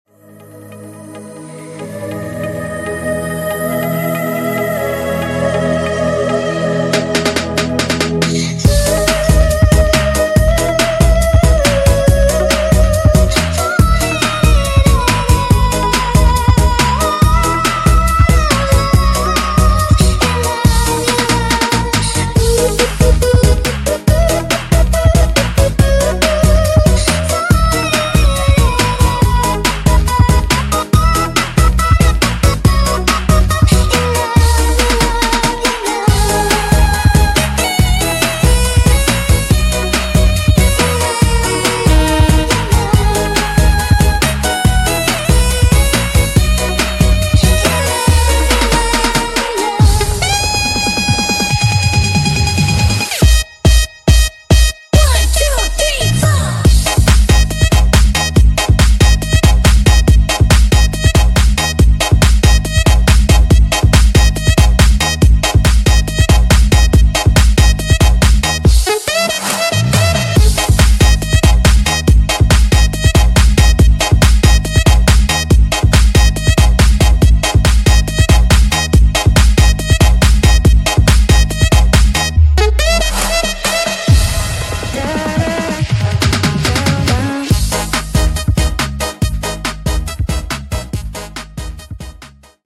Upacara 17 AGUSTUS 2025 sound effects free download